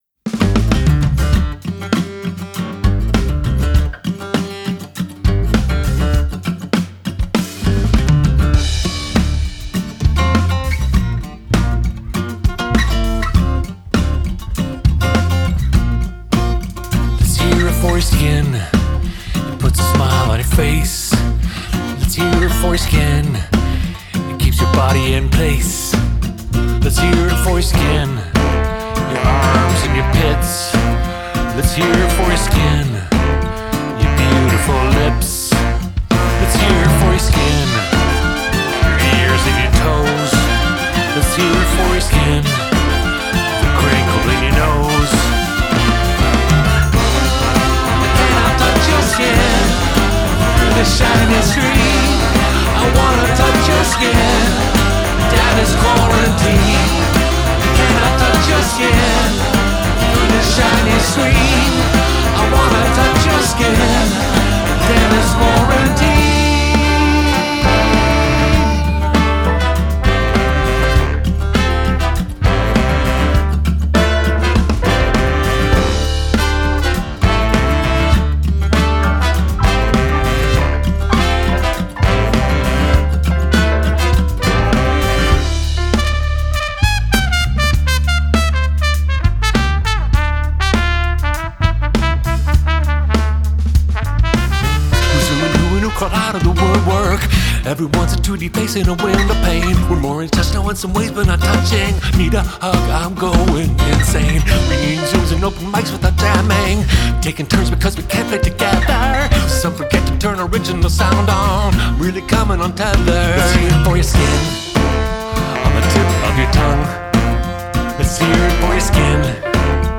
vocals, acoustic guitars [DADGAC], piano
drums, percussion
baritone saxophone
backing vocals